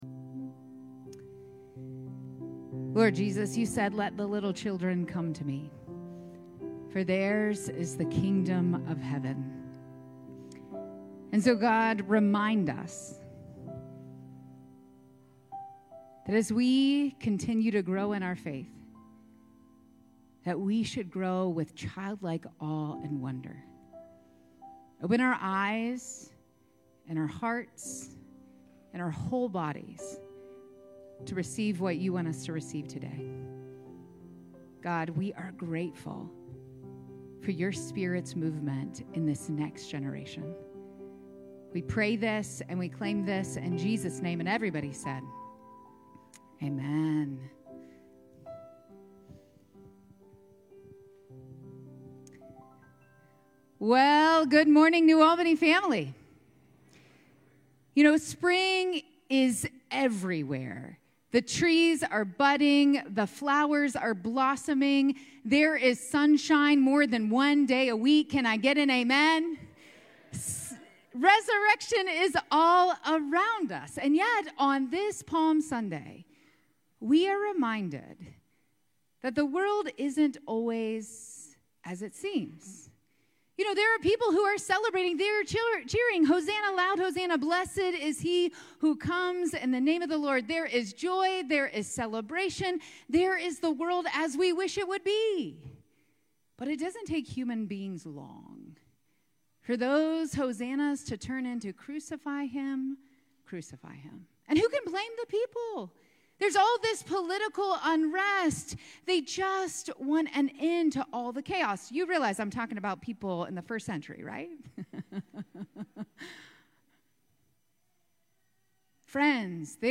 9:30 am Redemption Worship Service 04/13/25
Happy Palm Sunday!